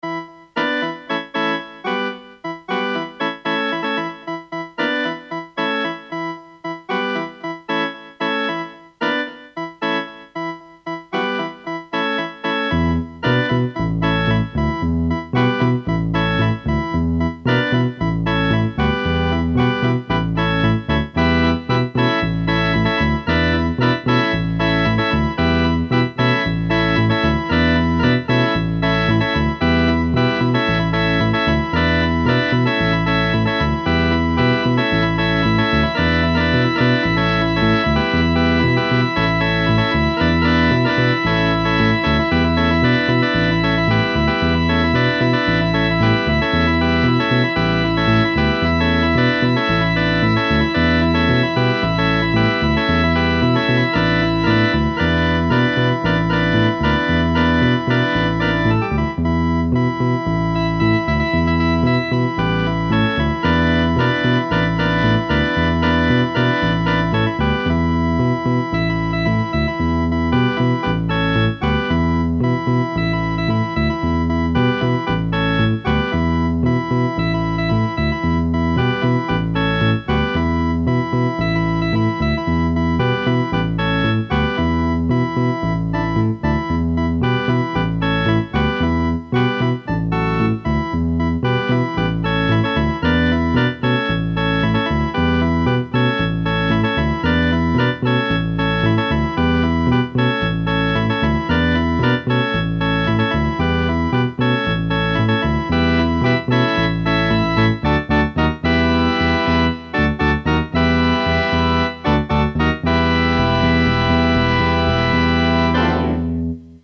sr-demo_g101_sy77_delay.mp3